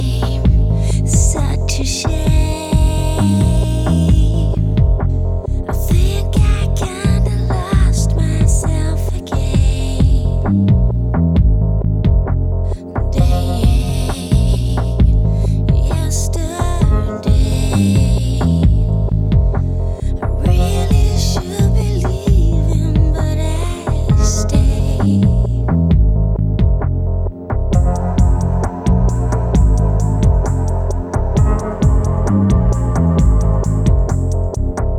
Жанр: Рок / Танцевальные / Альтернатива / Электроника / Джаз